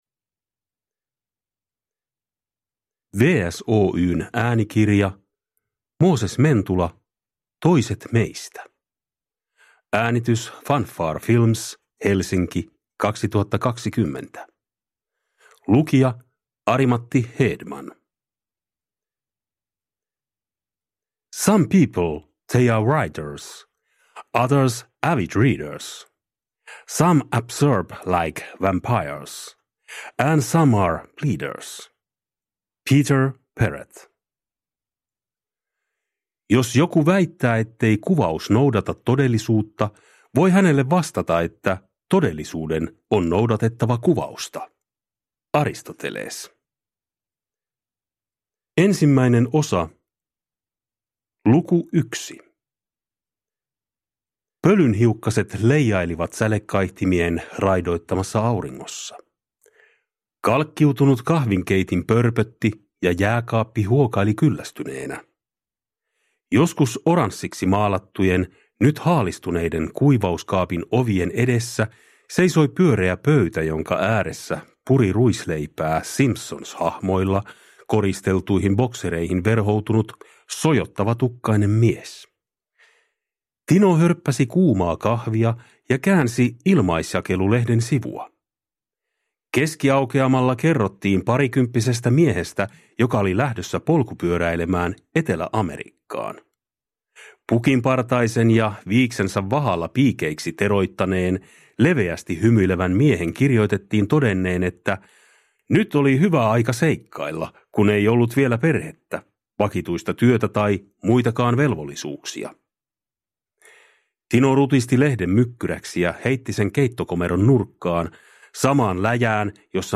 Toiset meistä (ljudbok) av Mooses Mentula